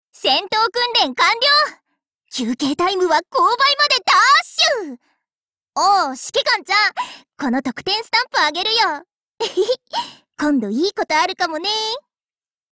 更改为语音分类